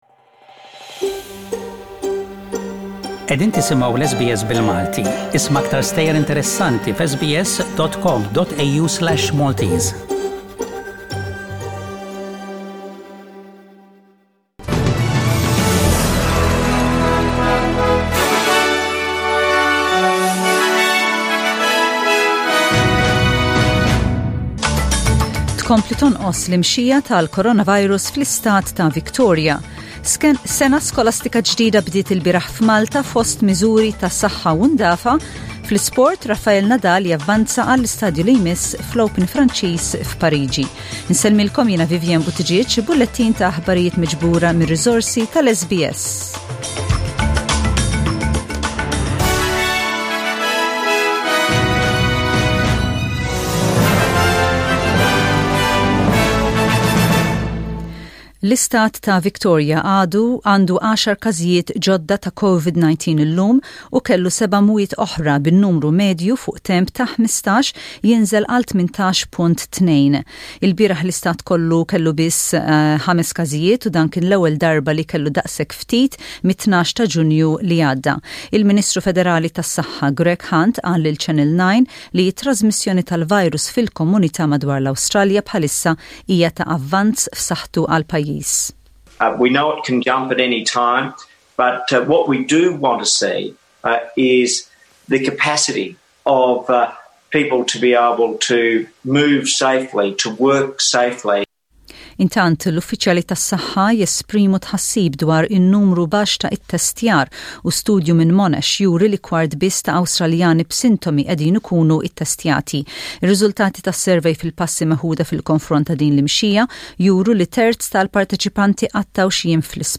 SBS Radio | News in Maltese: 29/09/2020